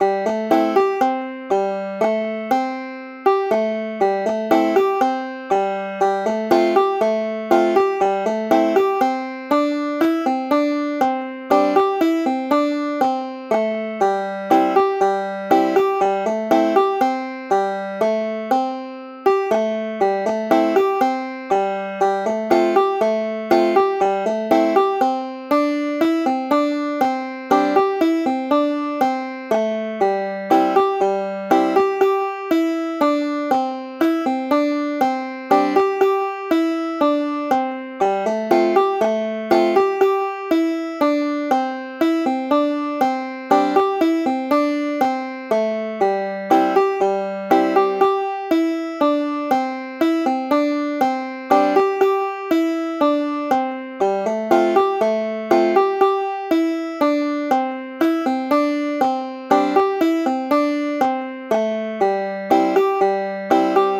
Hier habe ich einige Klangbeispiele zusammengestellt, welche teilweise mit Effekten versehen wurden.
ots-clawhammer-banjo-1.mp3